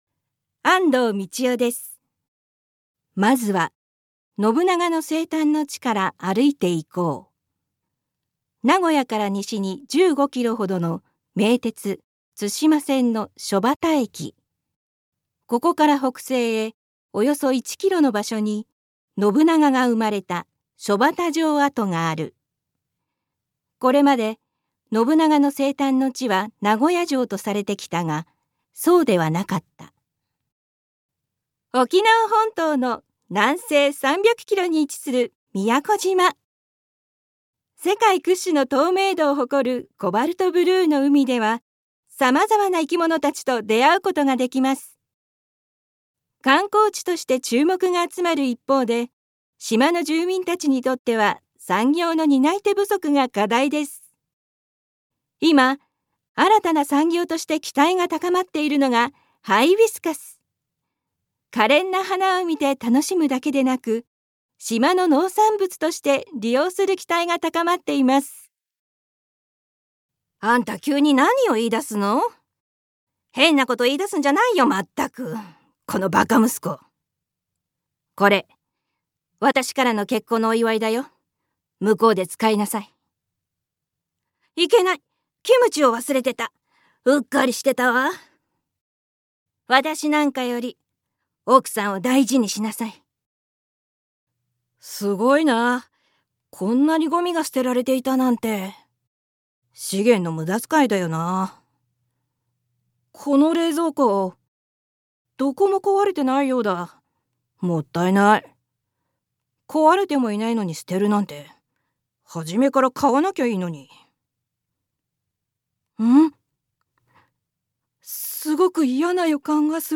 One shot Voice（サンプルボイスの視聴）